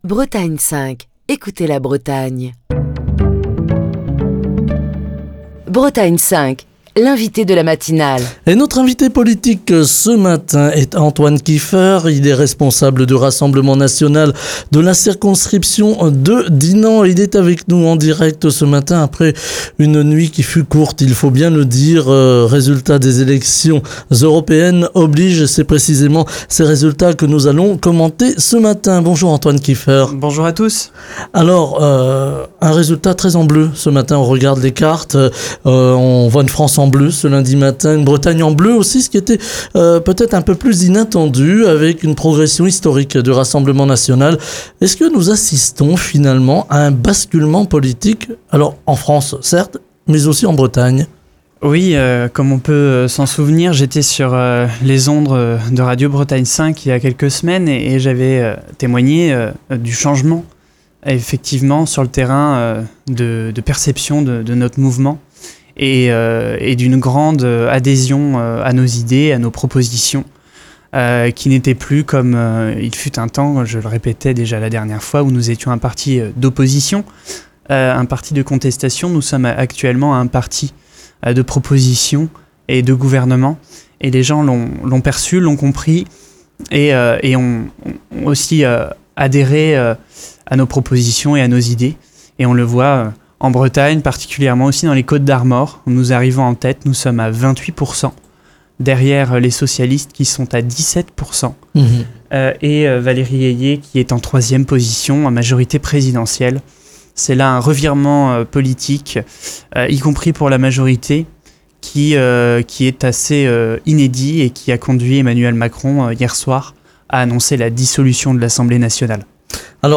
L'invité de Bretagne 5 Matin